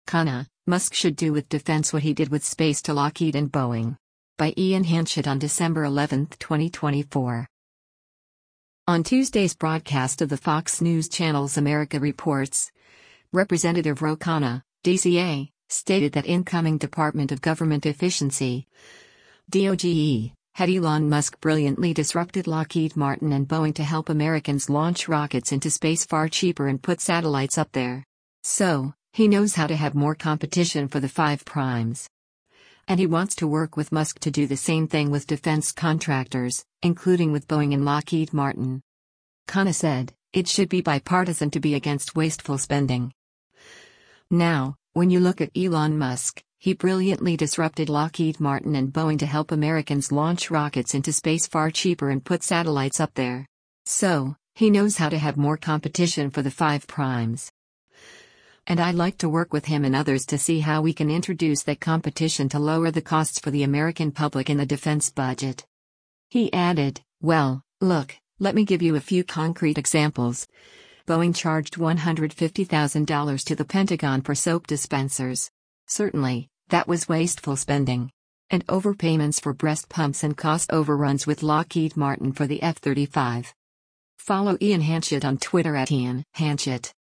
On Tuesday’s broadcast of the Fox News Channel’s “America Reports,” Rep. Ro Khanna (D-CA) stated that incoming Department of Government Efficiency (DOGE) head Elon Musk “brilliantly disrupted Lockheed Martin and Boeing to help Americans launch rockets into space far cheaper and put satellites up there.